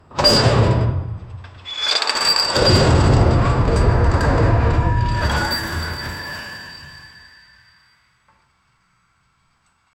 short-sound-of-a-massive-usmbod5o.wav